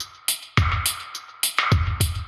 Index of /musicradar/dub-designer-samples/105bpm/Beats